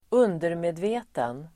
Ladda ner uttalet
Uttal: [²'un:derme:dve:ten]